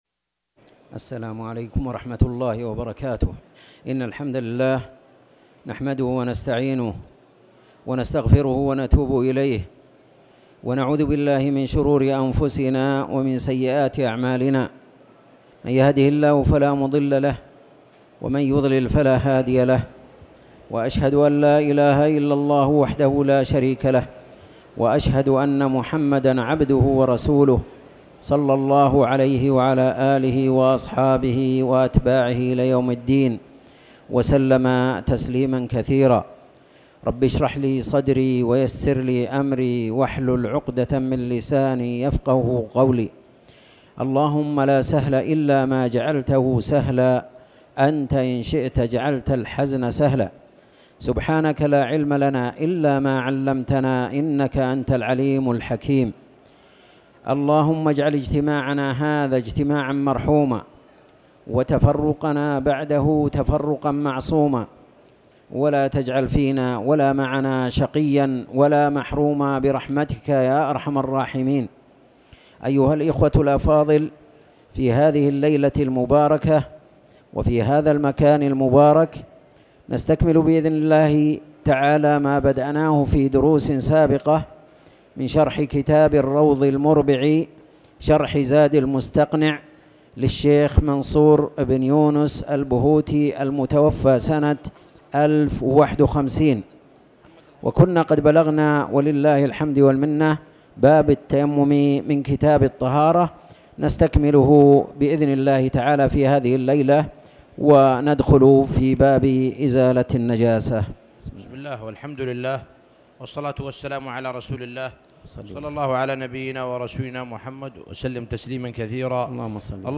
تاريخ النشر ٢٠ جمادى الآخرة ١٤٤٠ هـ المكان: المسجد الحرام الشيخ